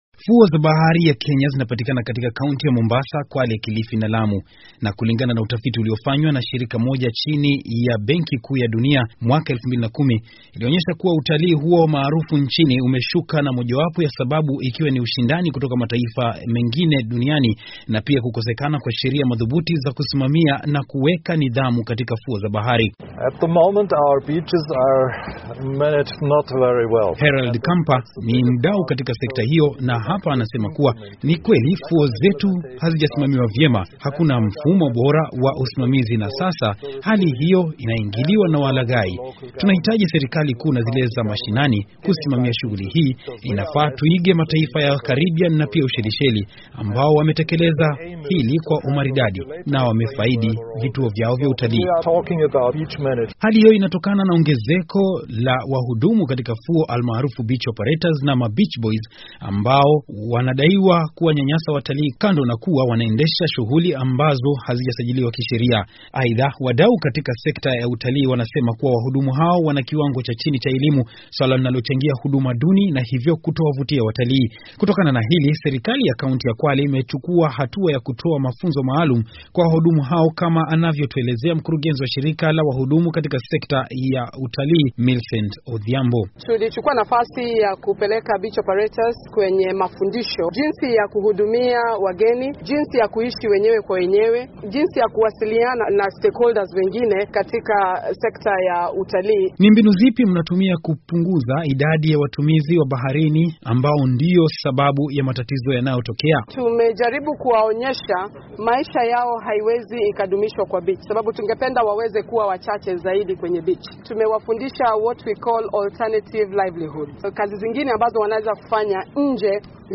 Ripoti kuhusu utalii Mombasa